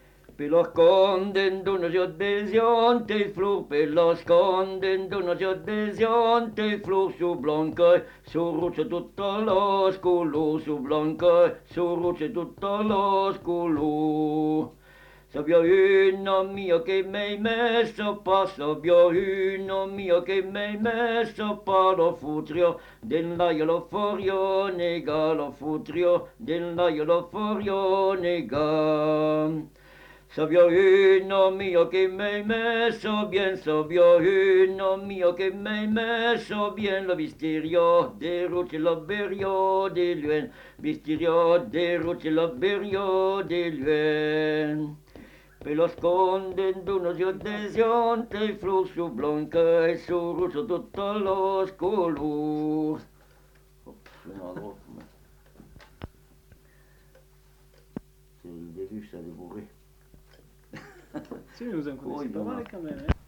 Lieu : Vernholles (lieu-dit)
Genre : chant
Effectif : 1
Type de voix : voix d'homme
Production du son : chanté
Danse : bourrée